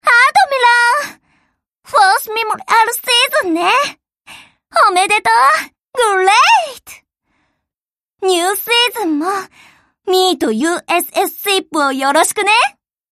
• She speaks Bonin English.